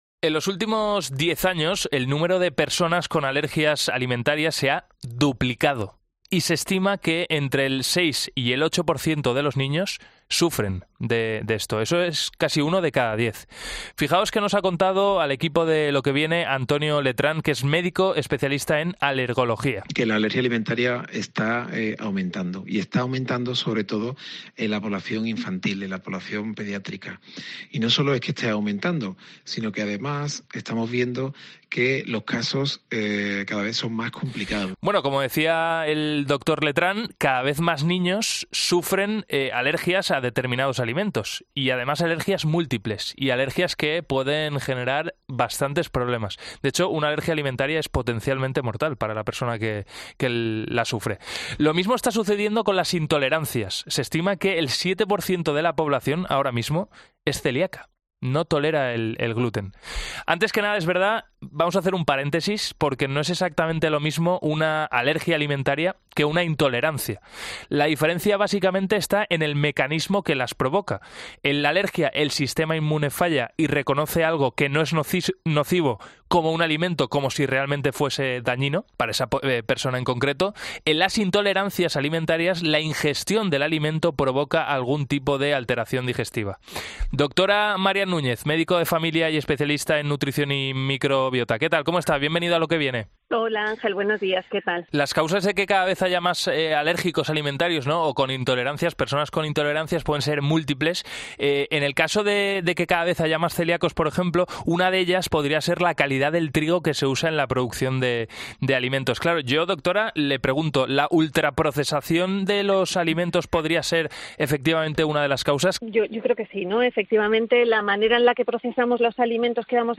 Escucha, en el siguiente audio, la conversación que hemos mantenido en 'Lo que viene' con los dos doctores sobre las intolerancias.